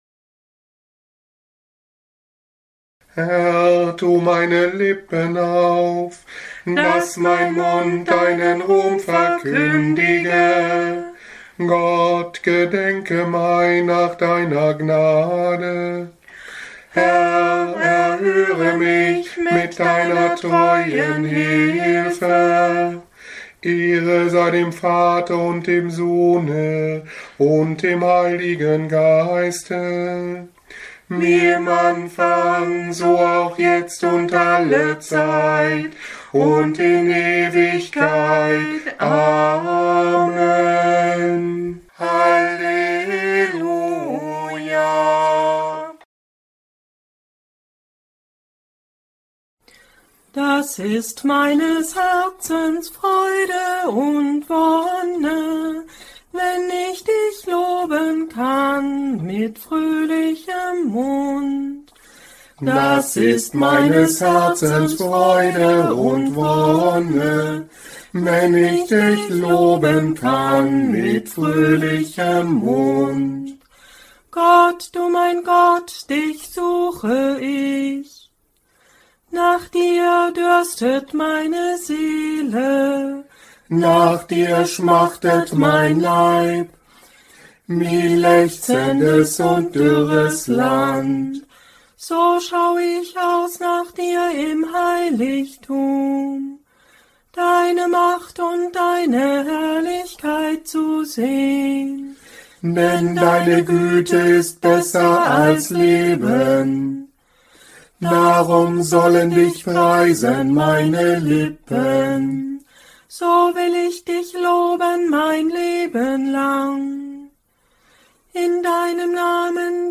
Morgengebet am Montag nach dem 12. Sonntag nach Trinitatis 2025 (8. September) Nummern im Tagzeitenbuch: 330, 721, 732, 220.2, 306, 222, Raum zum persönlichen Gebet, EGb S. 373